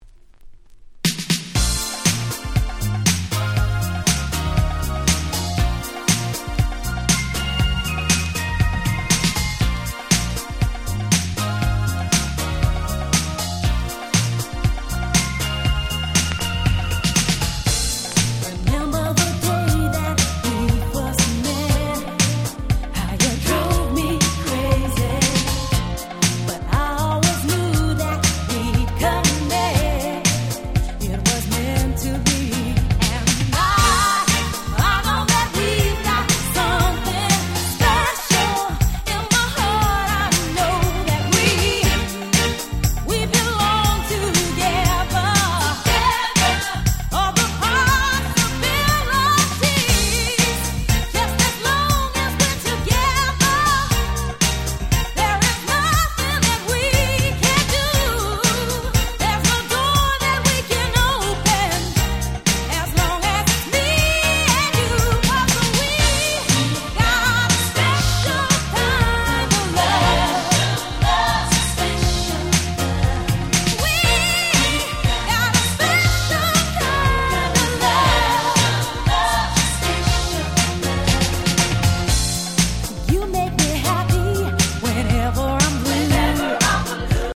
93' Nice R&B Album !!
90's キャッチー系 ボーカルハウス